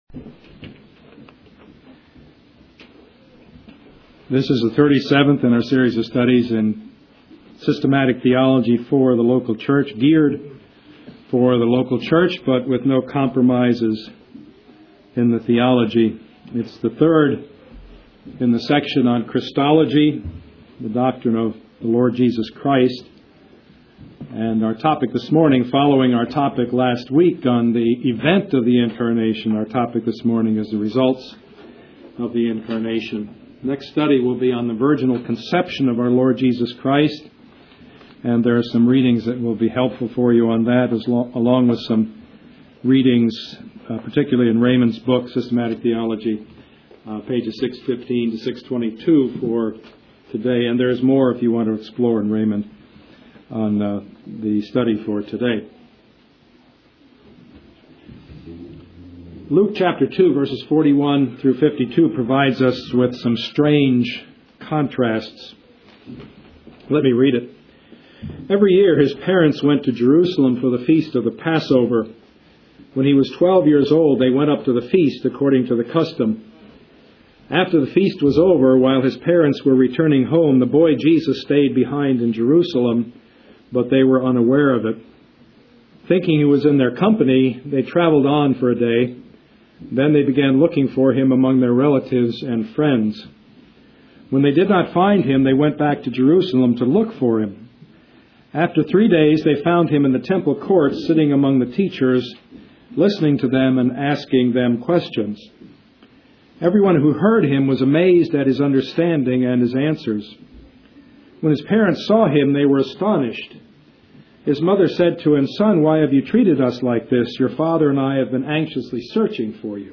Service Type: Sunday morning
Part 37 of the Sermon Series